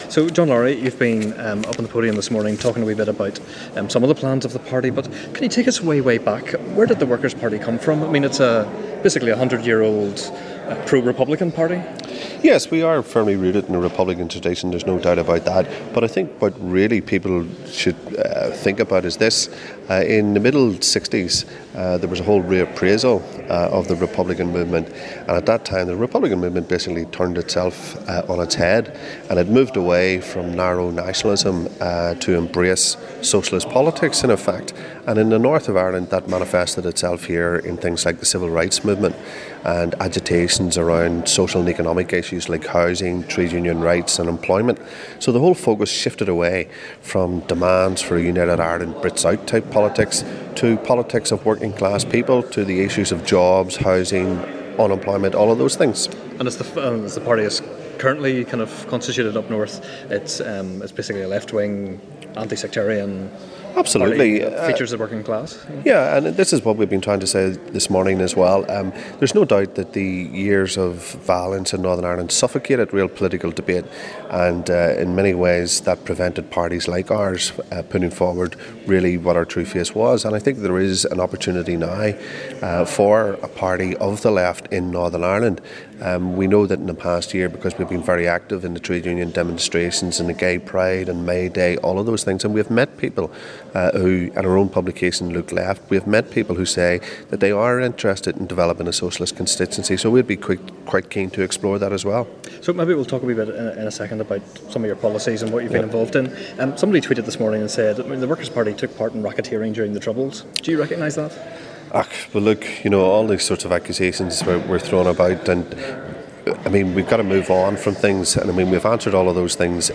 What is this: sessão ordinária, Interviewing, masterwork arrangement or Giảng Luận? Interviewing